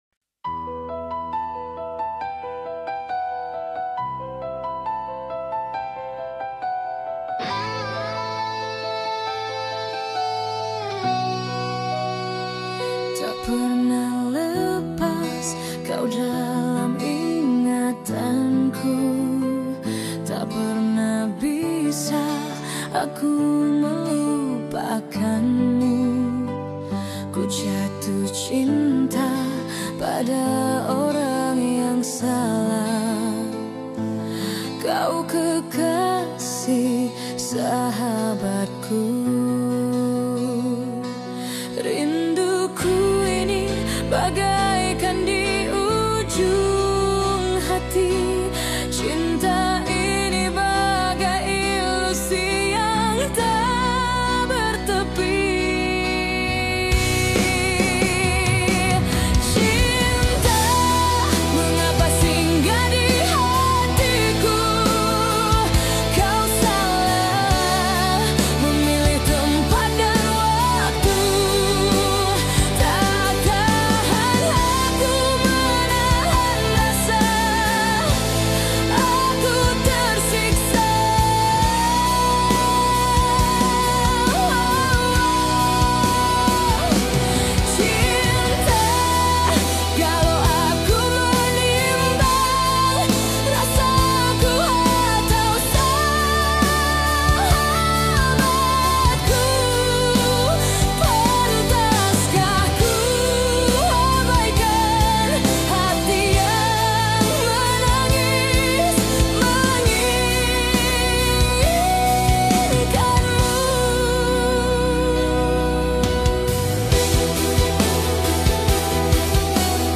ROCK SYMPHONI COVER